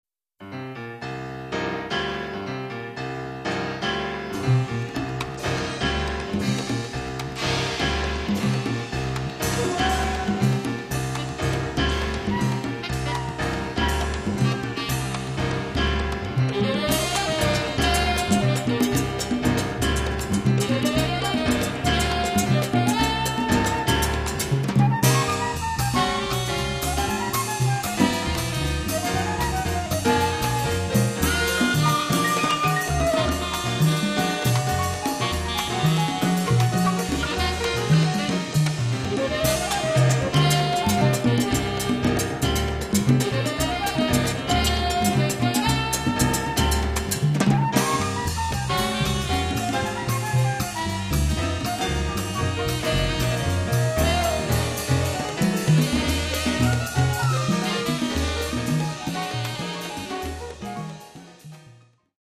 Timbales, Vibraphone